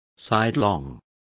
Complete with pronunciation of the translation of sidelong.